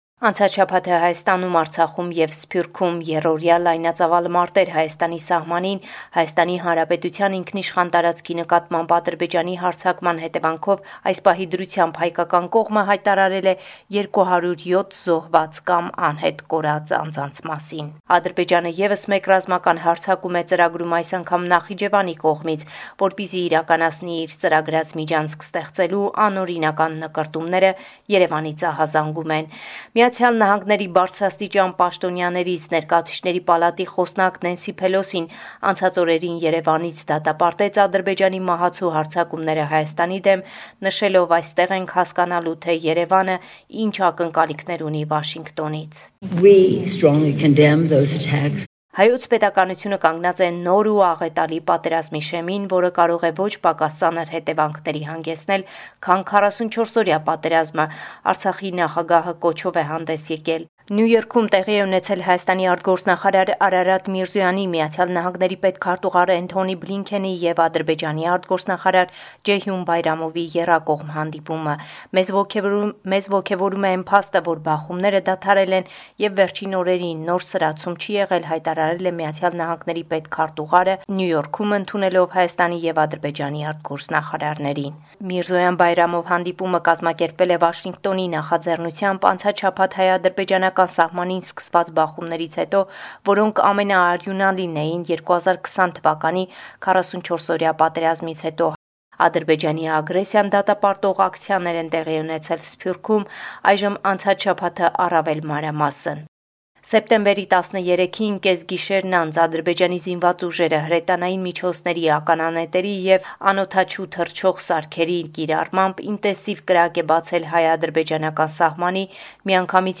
Latest News from Armenia – 20 September 2022